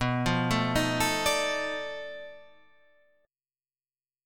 B7#9b5 chord